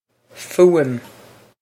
fuaim foo-im
This is an approximate phonetic pronunciation of the phrase.